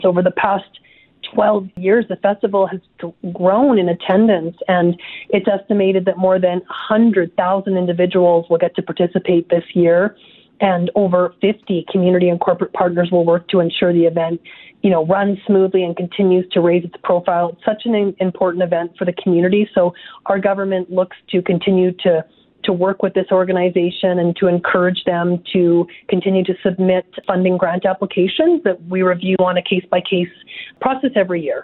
Tanya Fir, Minister of Arts, Culture, and status of women spoke with Windspeaker Radio Network about the funding contribution towards the Flying Canoe event.